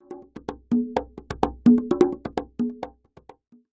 Music Transition; Gamelan Ethnic Drums Fade In And Out.